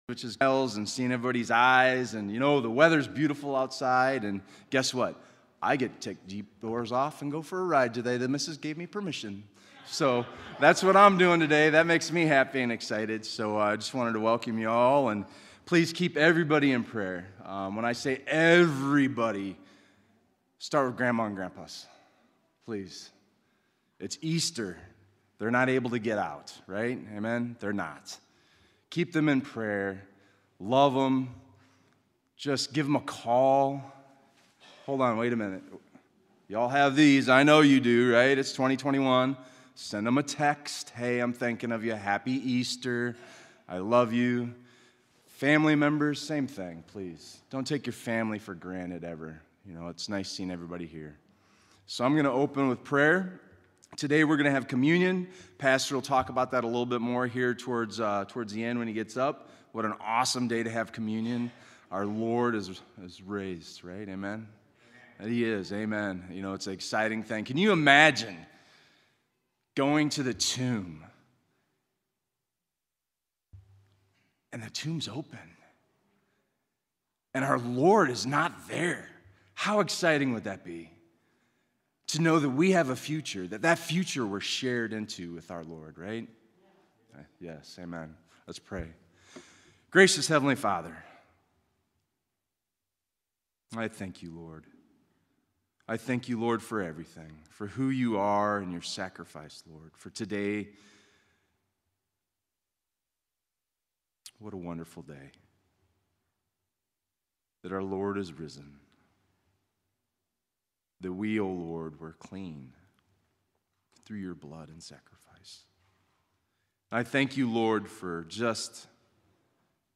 EASTER SERVICE